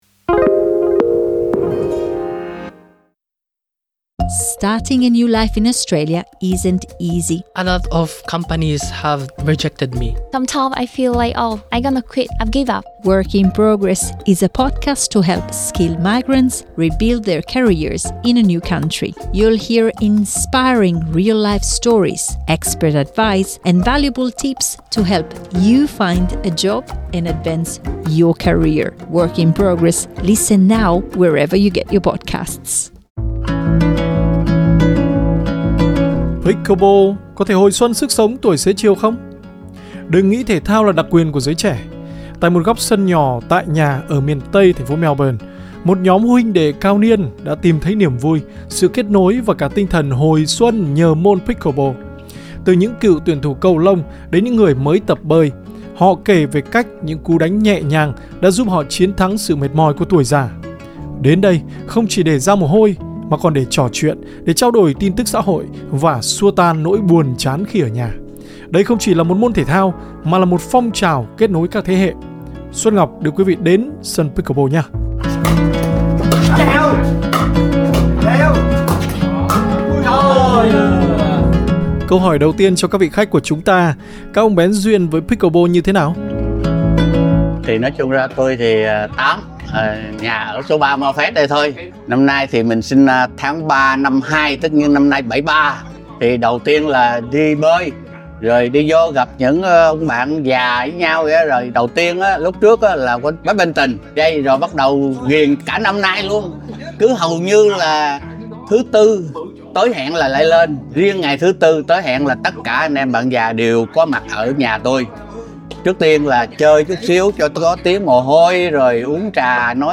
Tại một góc sân nhỏ tại nhà, ở miền Tây, Melbourne, một nhóm "huynh đệ cao niên" đã tìm thấy niềm vui, sự kết nối và cả tinh thần "hồi xuân" nhờ môn pickleball. Từ những cựu tuyển thủ cầu lông đến những người mới tập bơi, họ kể về cách những cú đánh nhẹ nhàng, đã giúp họ chiến thắng sự mỏi mệt của tuổi già.